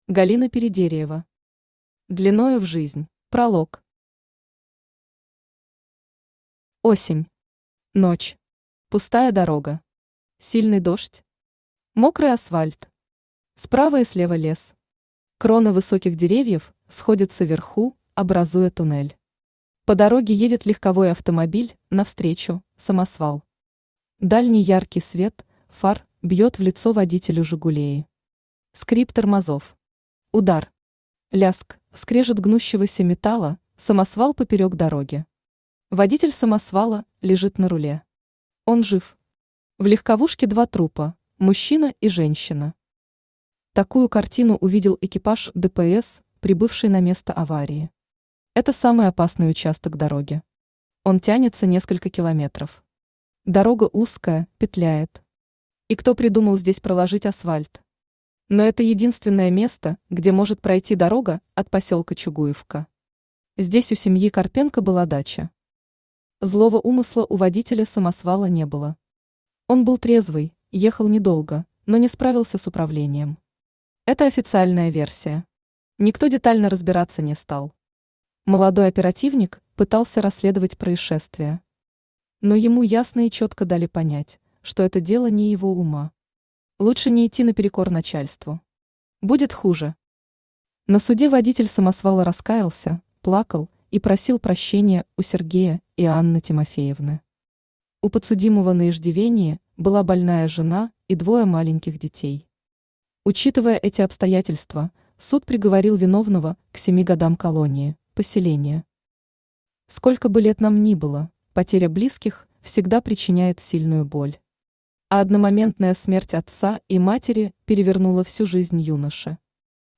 Аудиокнига Длиною в жизнь | Библиотека аудиокниг
Aудиокнига Длиною в жизнь Автор Галина Анатольевна Передериева Читает аудиокнигу Авточтец ЛитРес.